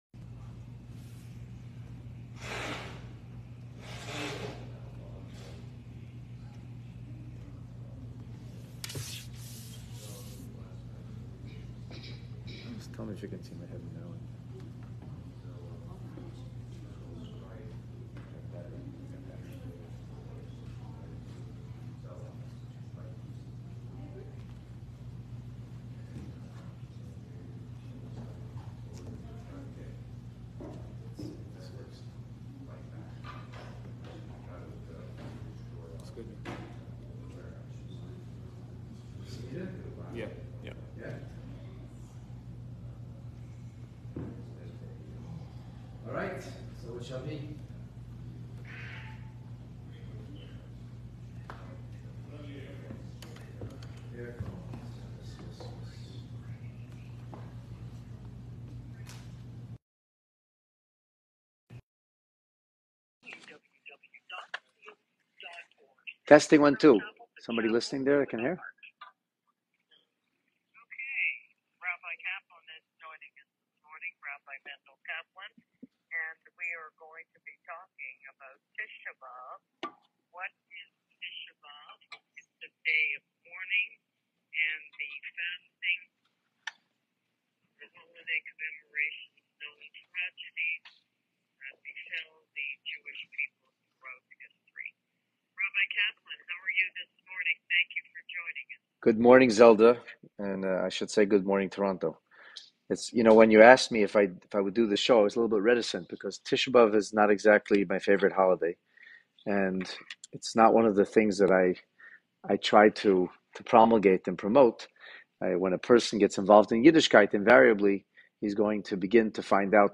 An illuminating conversation about Tisha B’Av on LIVE on CHIN Multicultural Radio 91.9 FM